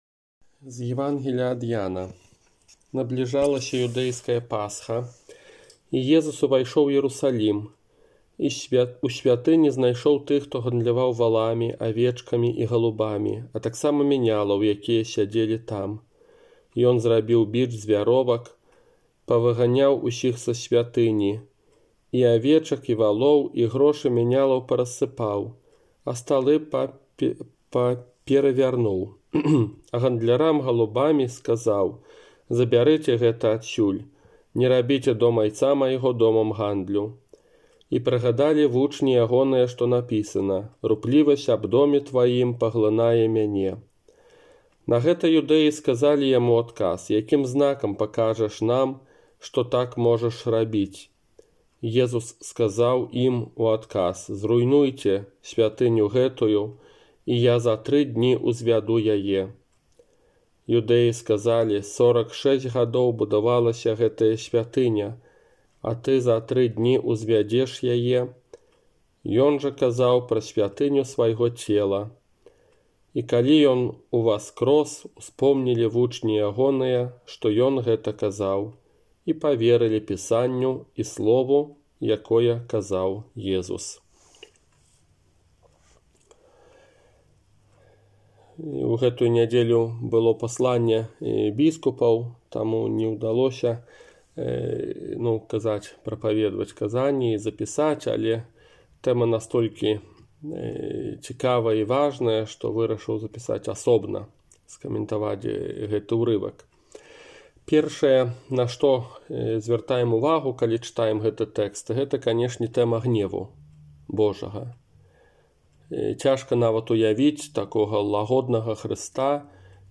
ОРША - ПАРАФІЯ СВЯТОГА ЯЗЭПА
Казанне на трэццюю нядзелю Вялікага Паста 7 сакавіка 2021 года